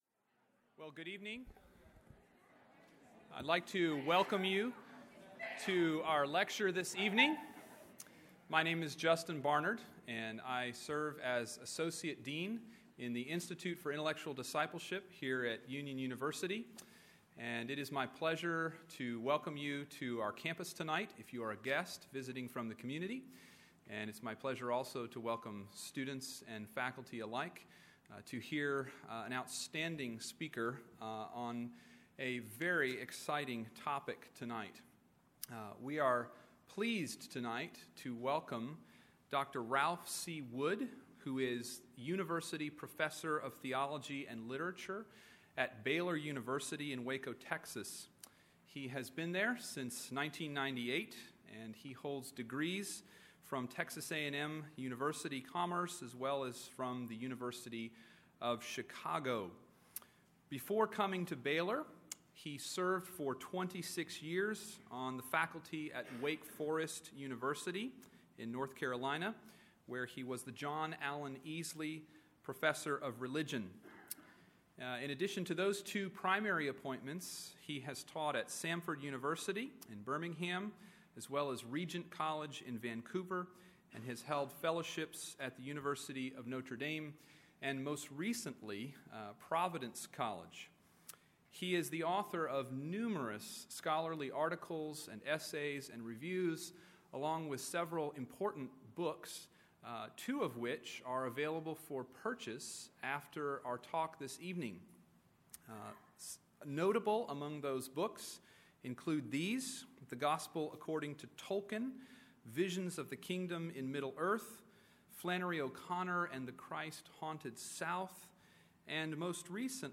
This lecture was focused on the Christian idea of beauty in all of its arresting strangeness—especially its insistence that an instrument of suffering and shame has become the Tree of Life, transforming human ugliness into divine loveliness. Specific attention was given to the fiction of Flannery O’Connor, the poetry of G. K. Chesterton, and the hymnody of Isaac Watts.